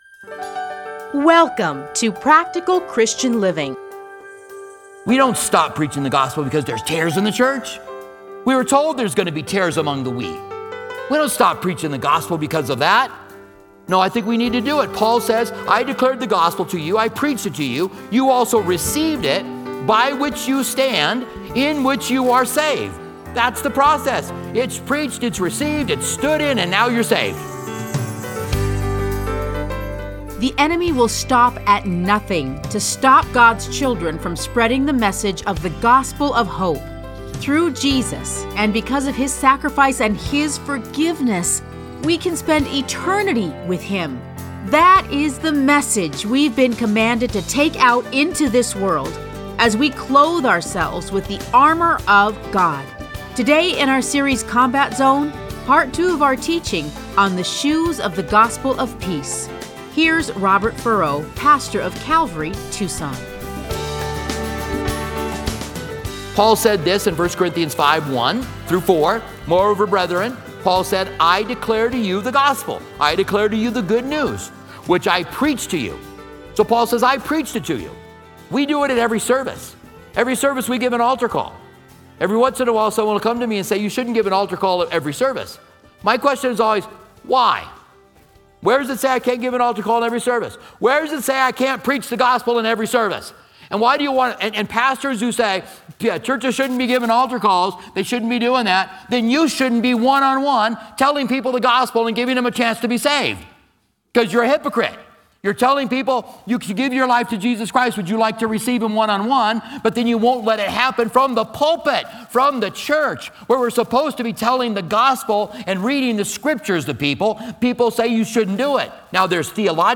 Listen to a teaching from Ephesians 6:15.